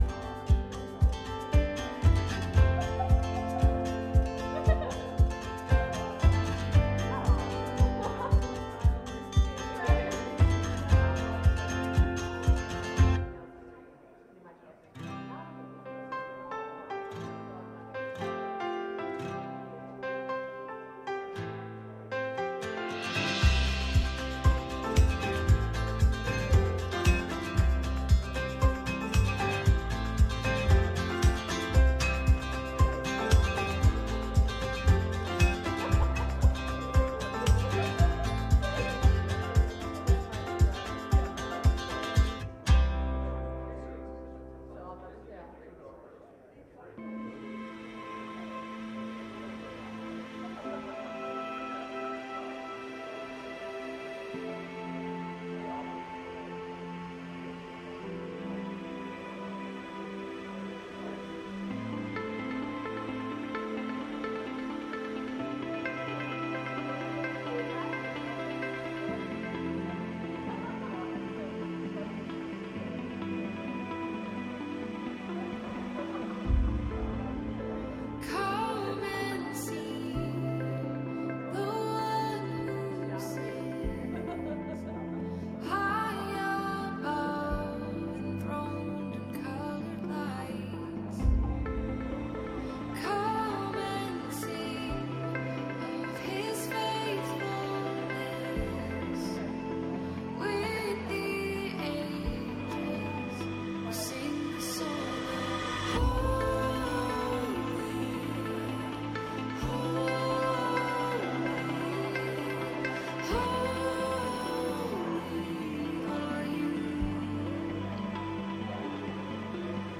Sermons | Saanich Baptist Church
Today was Palm Sunday, and we continued to explore the series, ‘We, the Church'.